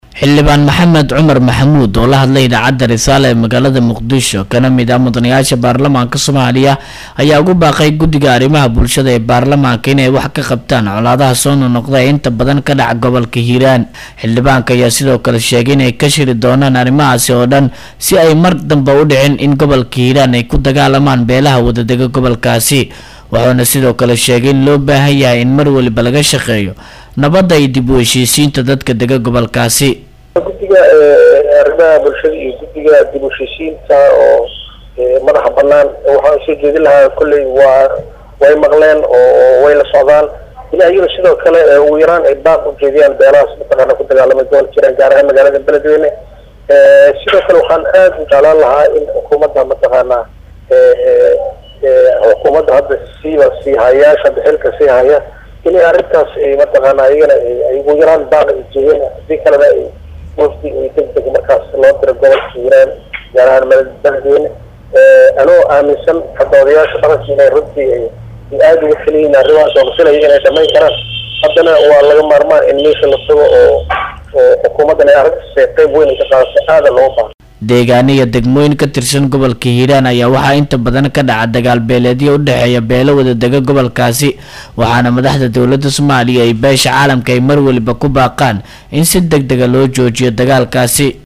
Muqdisho(INO)-Xildhibaan Maxamed Cumar oo la hadlay Idaacada Risaala ee Magaalada Muqdisho kana mid ah Mudanayaasha Baarlamaanka Soomaaliya ayaa ugu baaqay Guddiga arrimaha bulshada ee Baarlamaanka in ay wax ka qabtaan colaadaha soo noq noqda ee ka dhaca Gobolka Hiiraan.